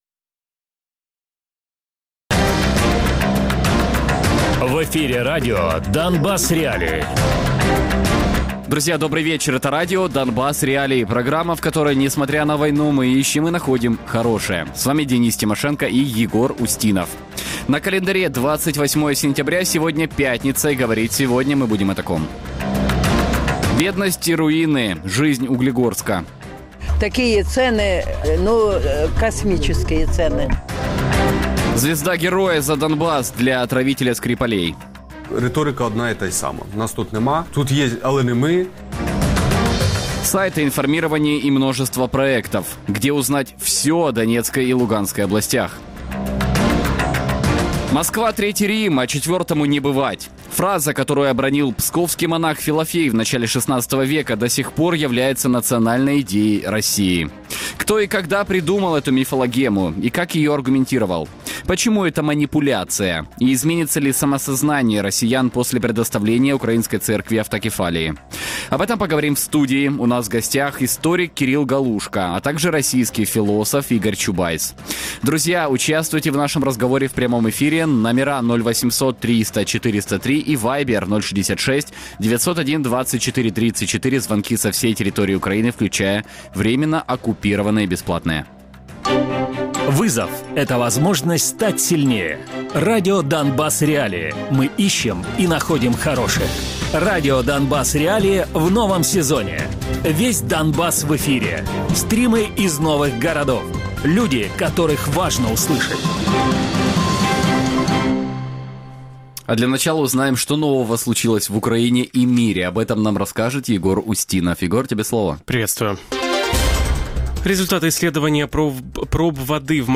доктор філософських наук Радіопрограма «Донбас.Реалії» - у будні з 17:00 до 18:00.